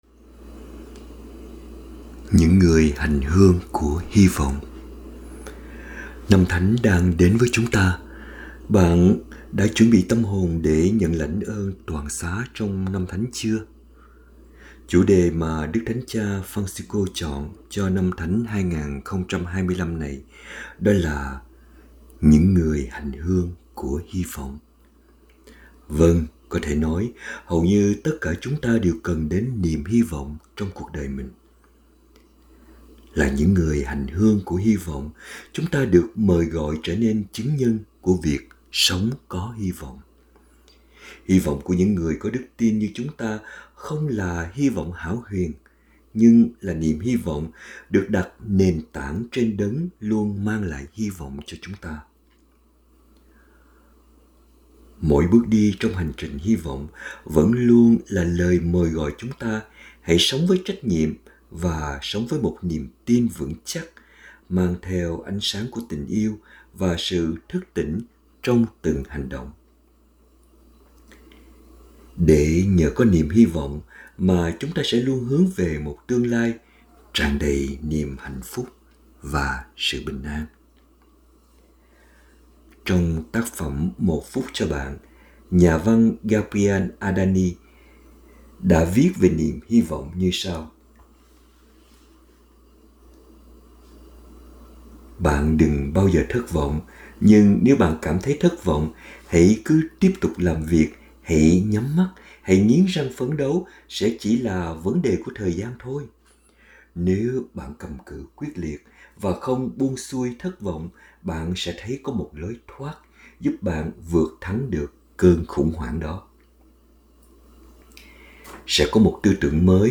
2025 Audio Suy Niệm https